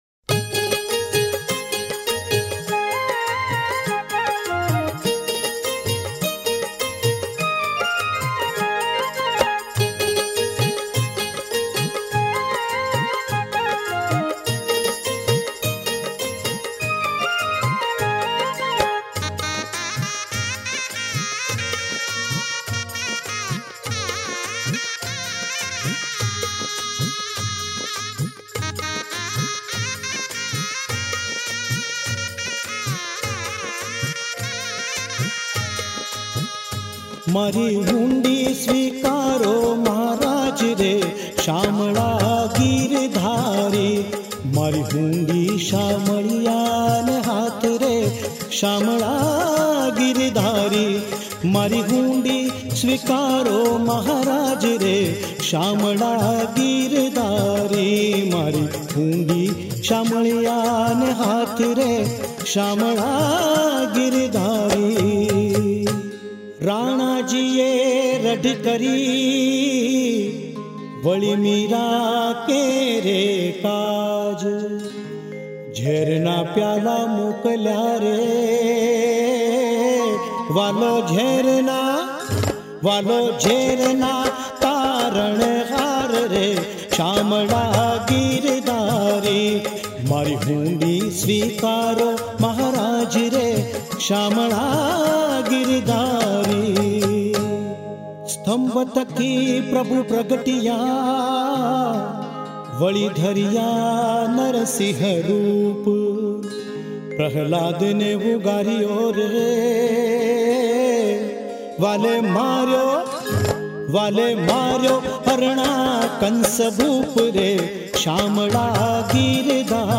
विशेष :- सभी भजन  पार्श्व भजन गायको के स्वर में है